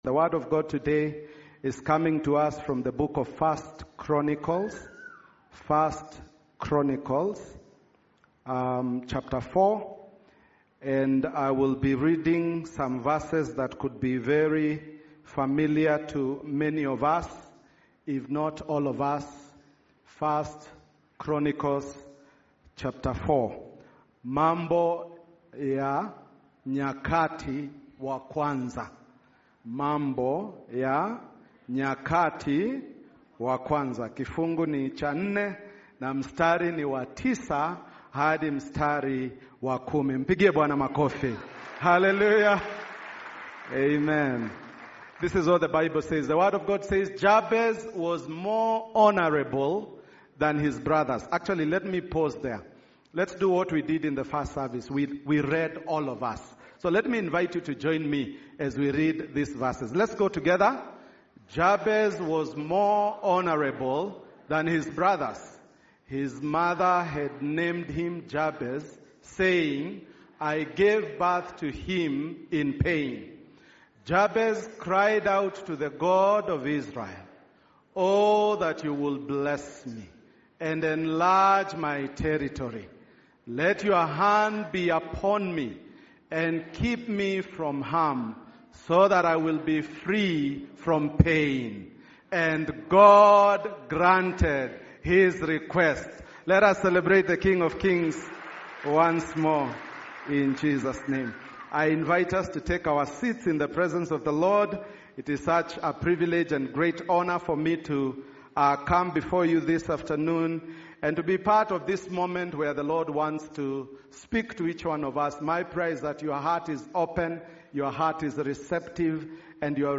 8thJuneSundayServiceSermon_v01.mp3